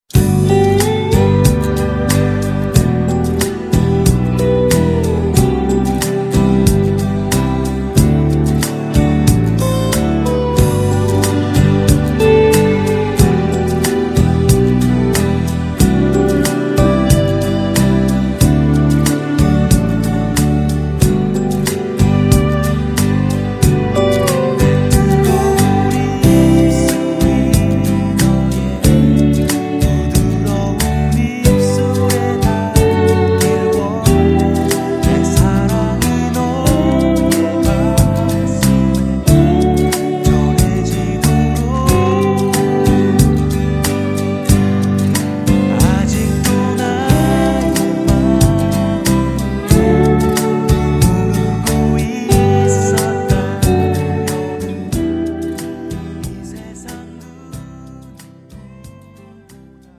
음정 원키
장르 가요 구분
가사 목소리 10프로 포함된 음원입니다